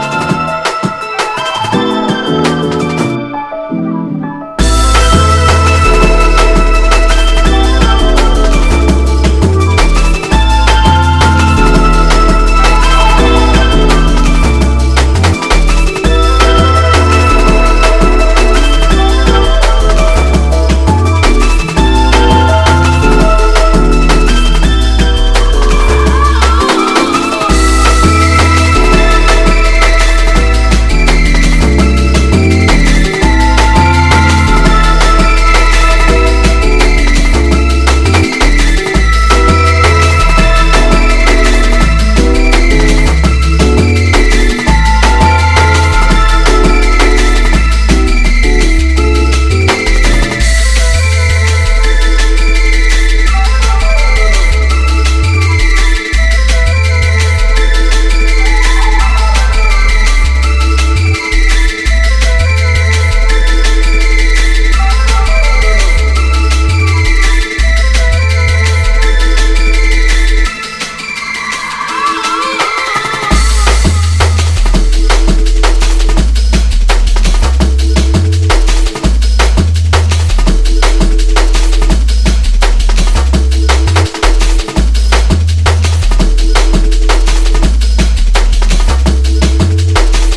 Drum & Bass / Jungle